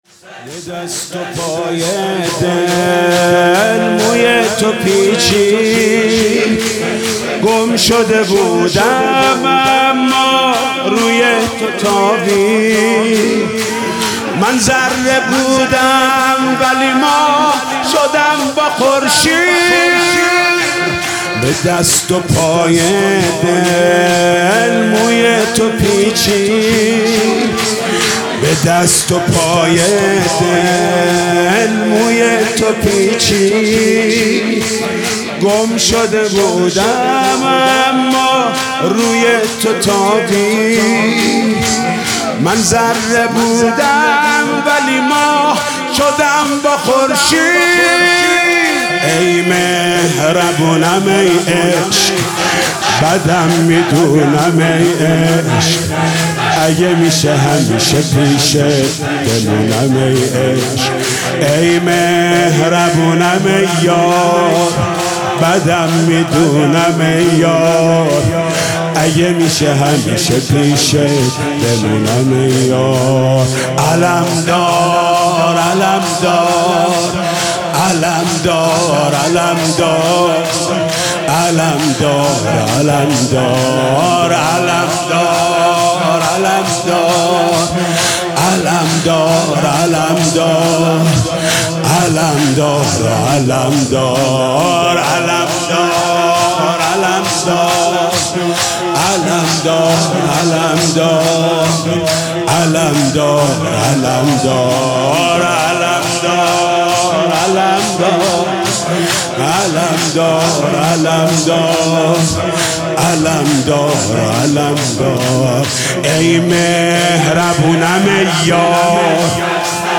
شور | به دست و پای دل موى تو پیچید
مداحی حاج محمود کریمی | شب اول محرم 1397 | هيأت‌ راية ‌العباس ( عليه ‌السلام )